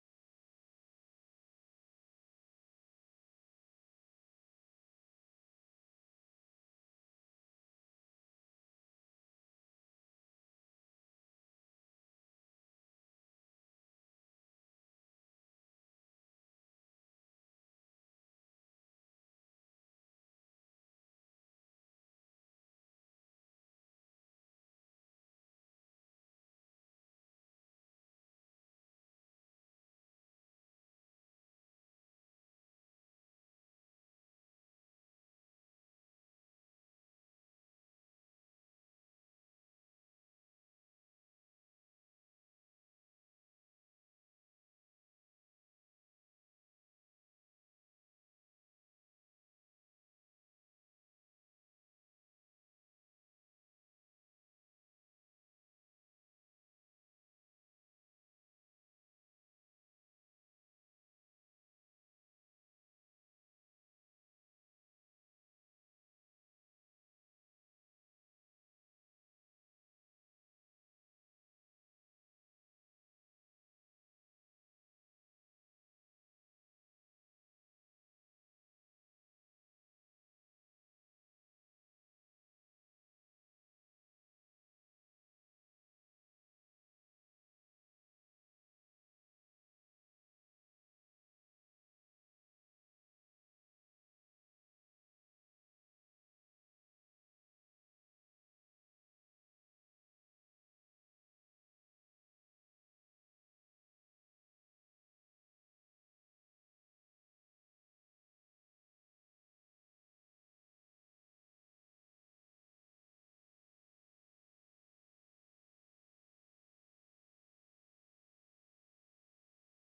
Sermon for Christmas Day